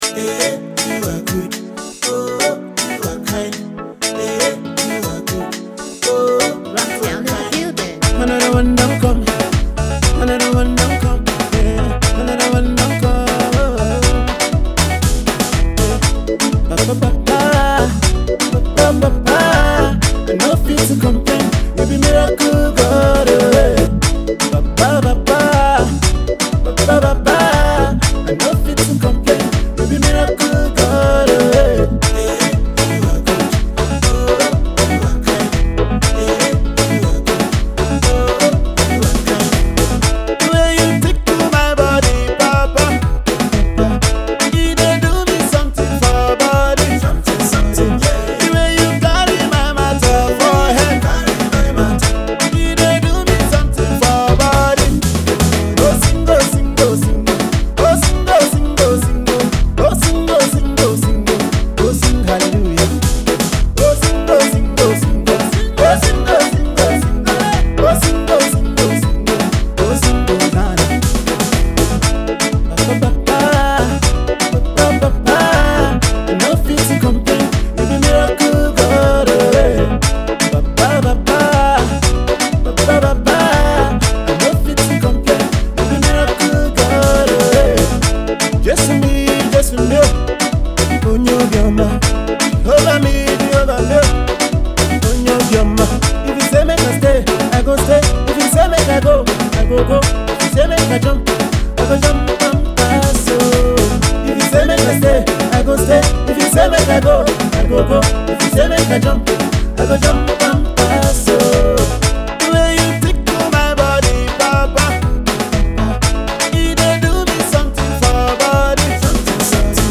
another groovy beats and rhythm.